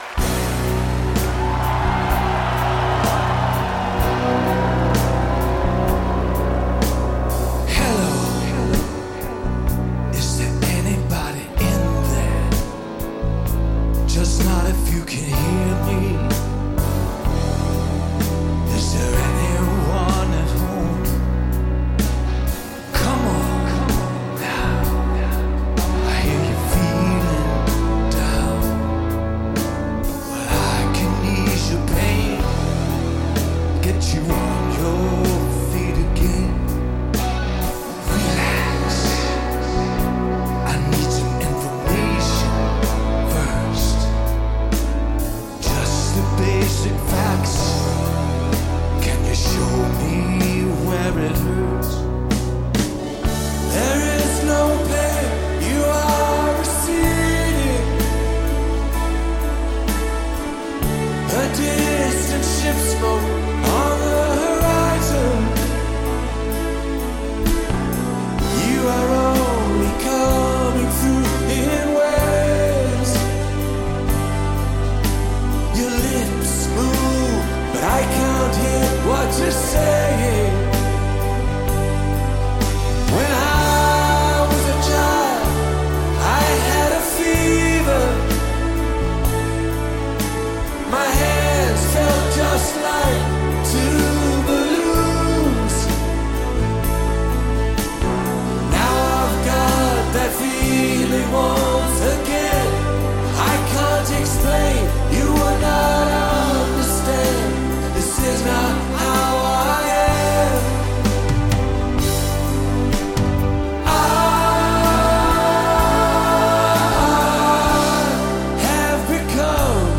Rock
گیتار سولو